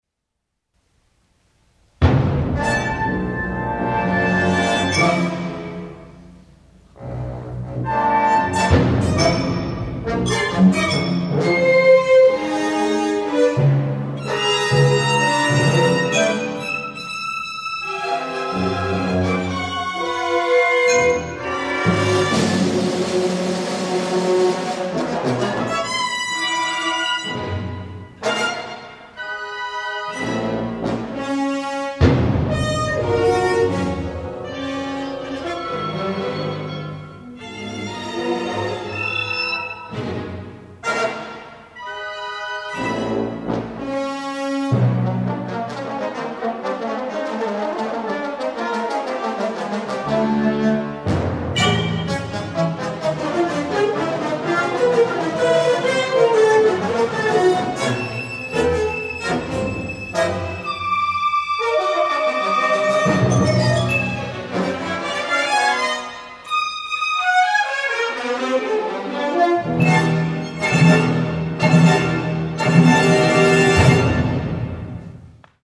short orchestral work